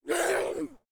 femalezombie_spotted_04.ogg